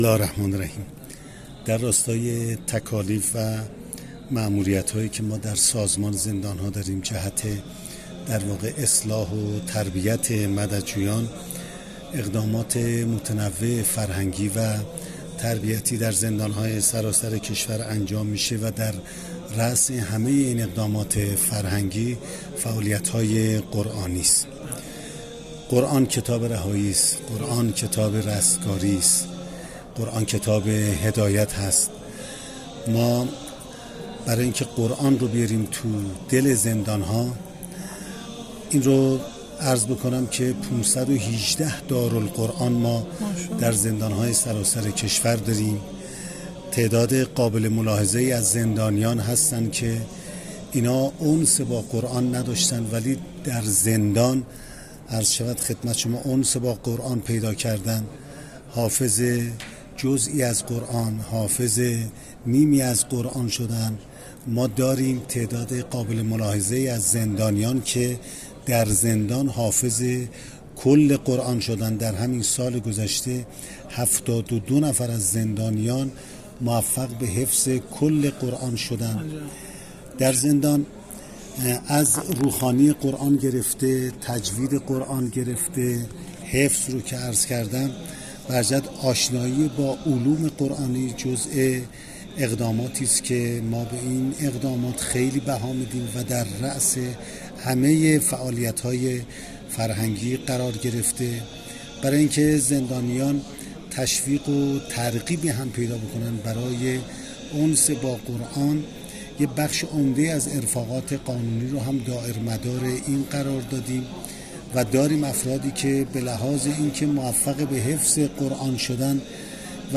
غلامعلی محمدی، رئیس سازمان زندان‌ها
حال برای اطلاع بیشتر از اقدامات سازمان زندان‌ها در عرصه‌های مختلف فرهنگی و قرآنی برای مددجویان سراسر کشور به سراغ غلامعلی محمدی، رئیس سازمان زندان‌ها، رفتیم که مشروح این مصاحبه در ادامه آمده است.